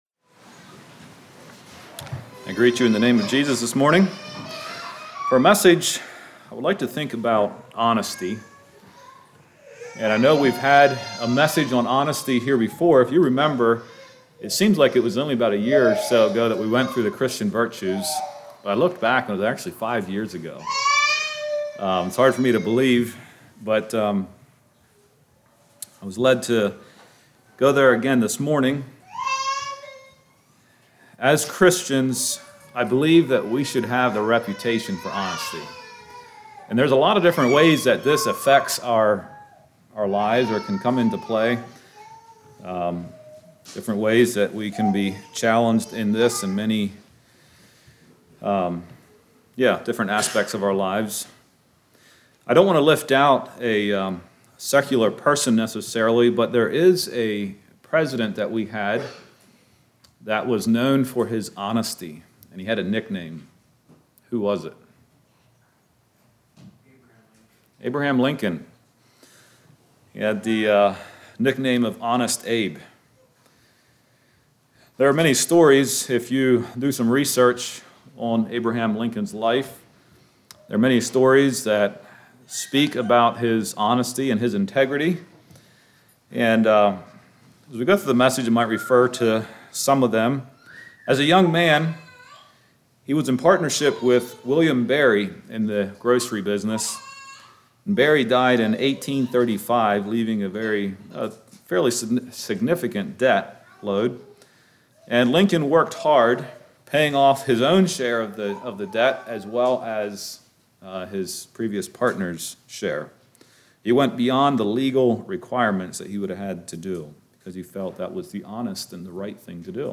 Play Now Download to Device Honesty Congregation: Tyrone Speaker